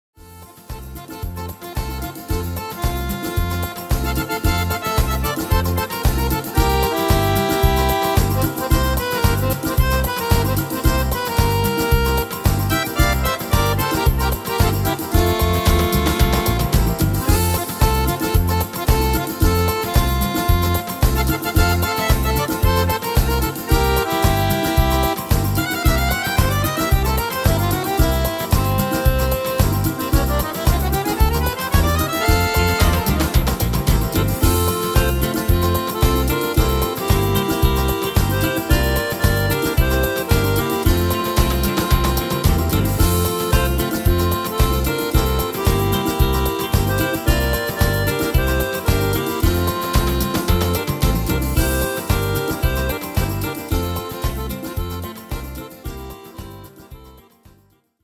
Tempo: 112 / Tonart: F-Dur